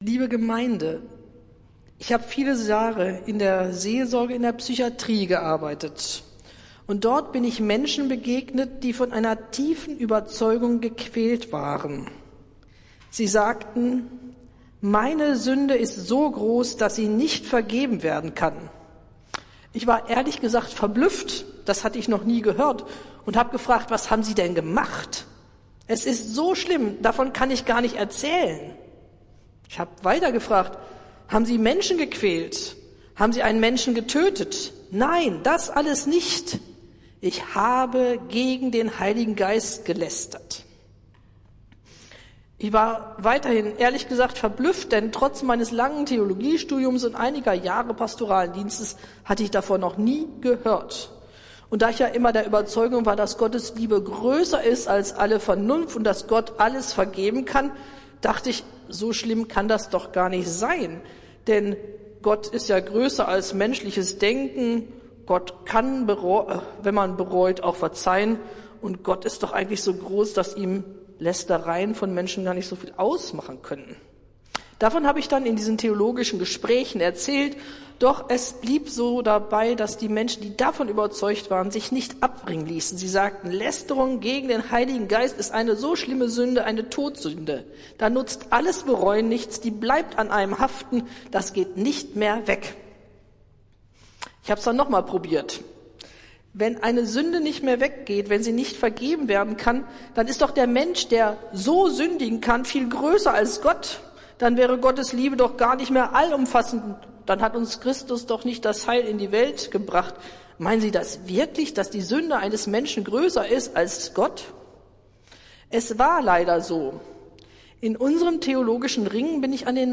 Predigt des Gottesdienstes aus der Zionskirche vom Sonntag, den 14. Januar 2024